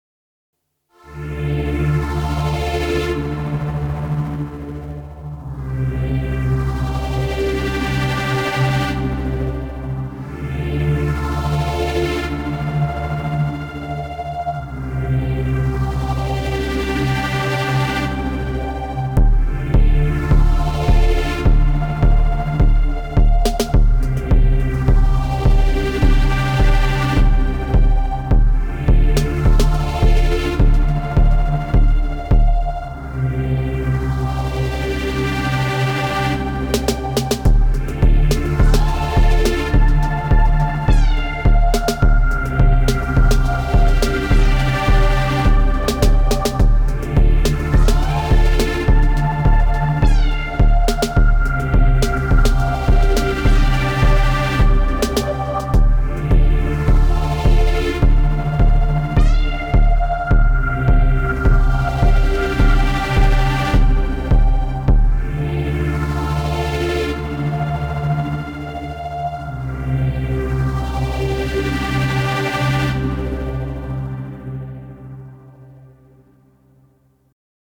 Some recent string ensemble type sound explorations.
It’s a couple of Swarm instances with amplitude modulation from an LFO with some fade in to get the bowing kind of sound.
The phaser-ish sound is the FX track notch filter being modulated.